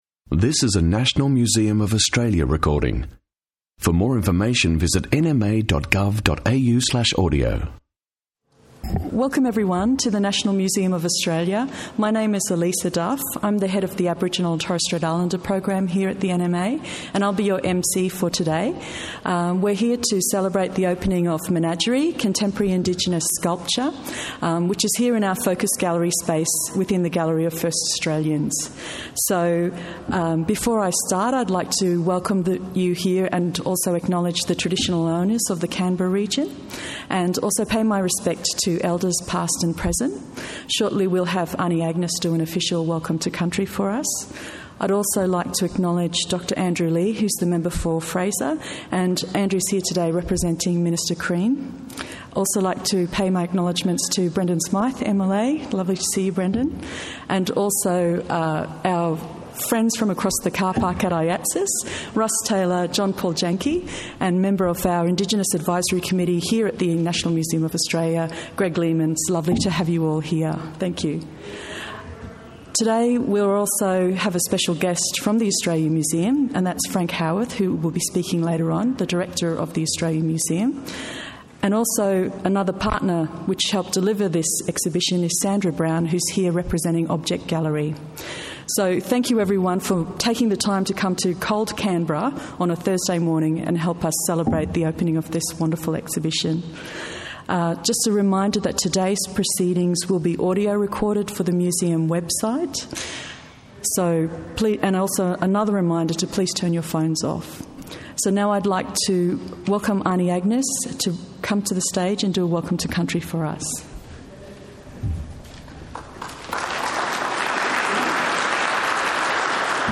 Menagerie: Contemporary Indigenous Sculpture: exhibition opening | National Museum of Australia